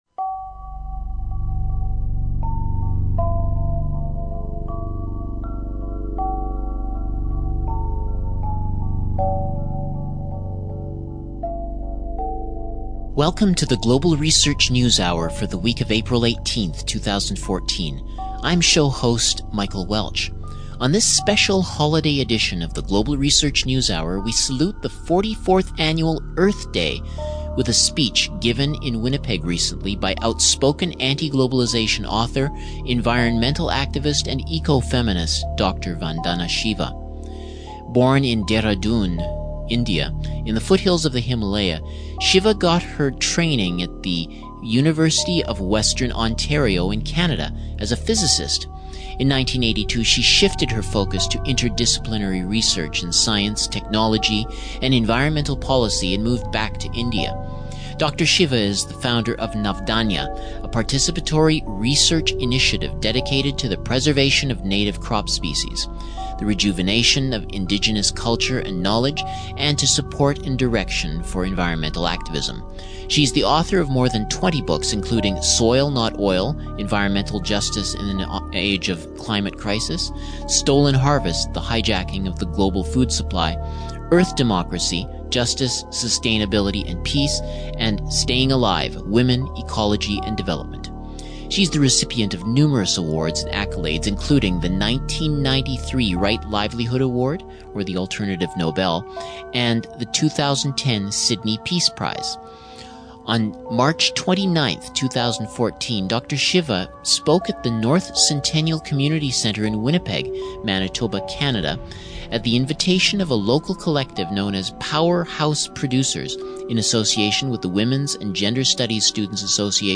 A recording of a March 2014 Winnipeg talk by Dr. Vandana Shiva